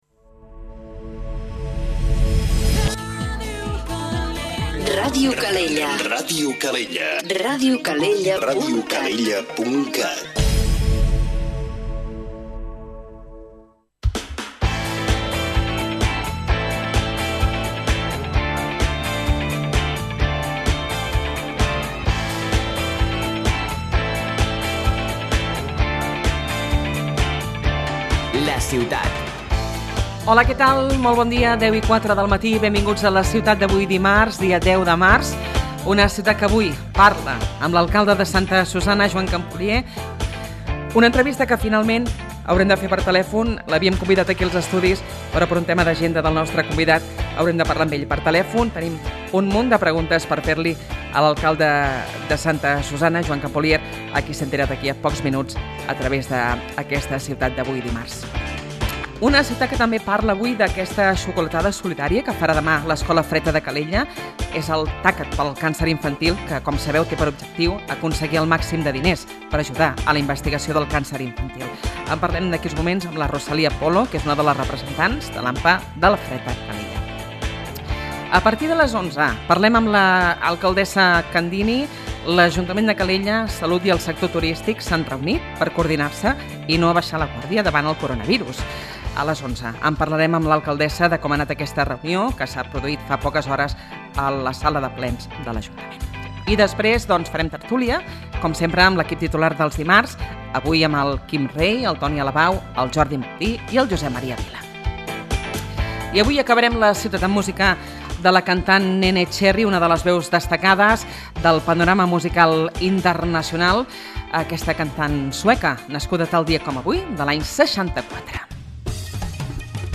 Aquest matí a La Ciutat hem conversat amb Joan Campolier, alcalde de Santa Susanna. Una entrevista que ens ha deixat alguns titulars destacats, com son aquestes 22.000 places hoteleres a les que vol arribar la població amb la construcció de nous establiments hotelers, i la conseqüent ampliació d’equipaments que donin sortida a la demanada lúdica dels visitants que rebran.